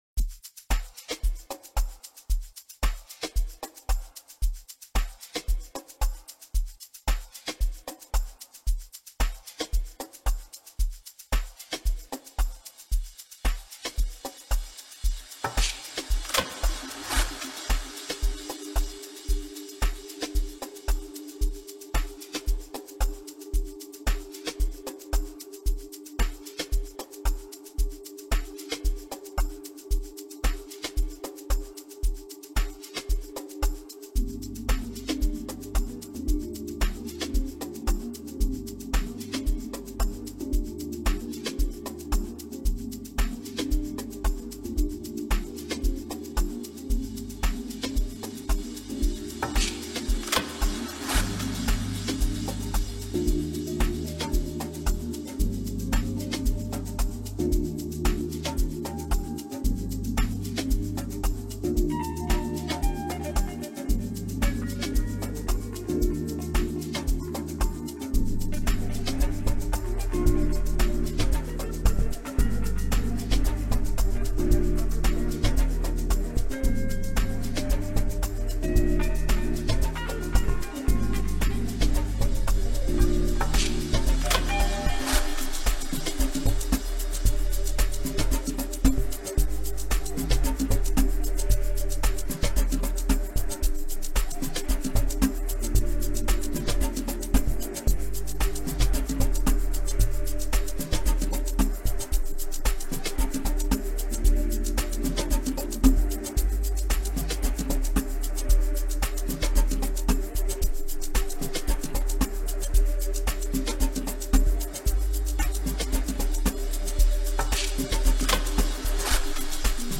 One of the hottest name in the Amapiano music scene
Bridging the gap between PSP and modern Amapiano
melodic and sentimental style